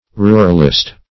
Ruralist \Ru"ral*ist\, n.